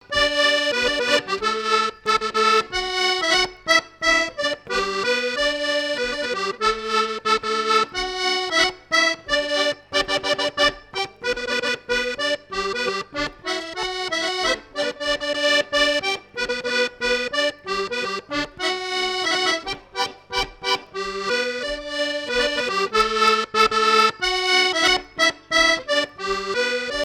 Chants brefs - A danser
danse : scottich trois pas
Fête de l'accordéon
Pièce musicale inédite